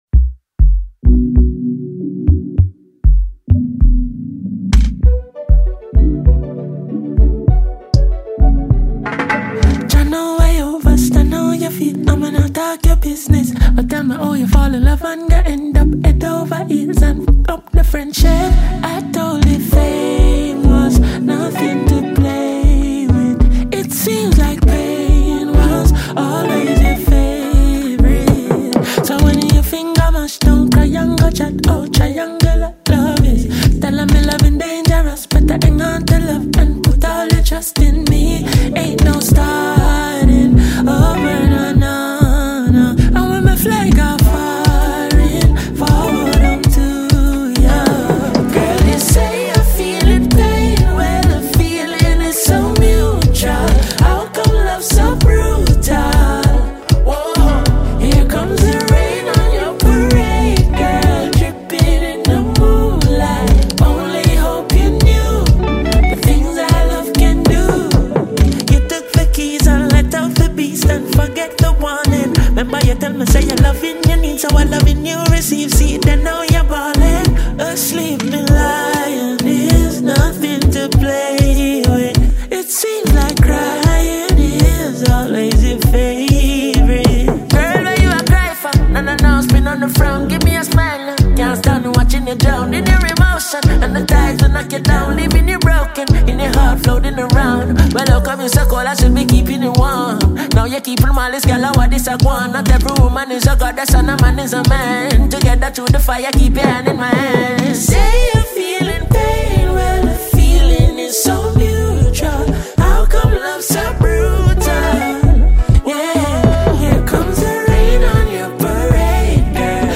reggae song